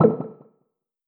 now-playing-pop-in.wav